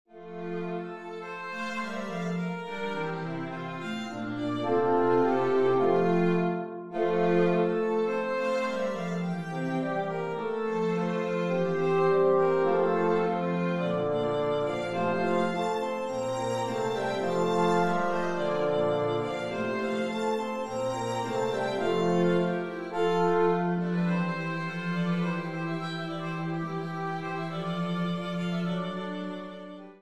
Kammerorchester-Sound